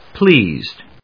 /plíːzd(米国英語), pli:zd(英国英語)/